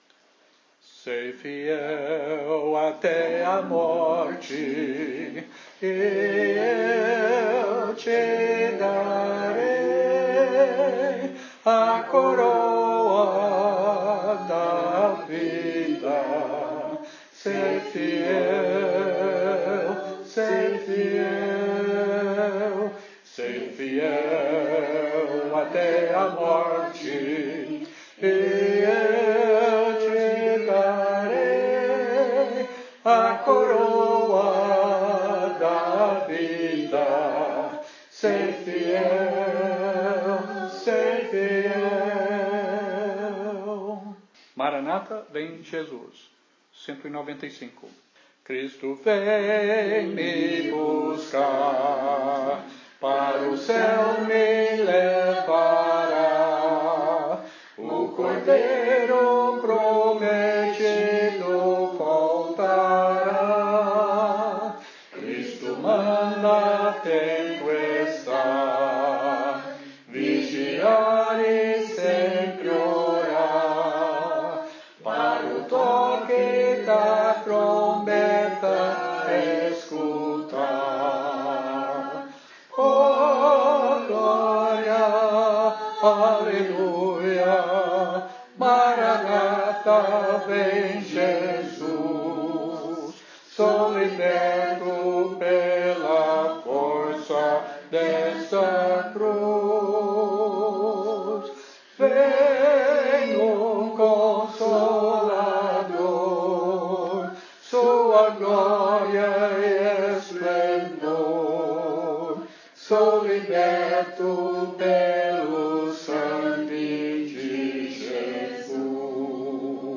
Reunião e mensagem na congregação no Urbanova, SJCampos SP, no 2020-05-17: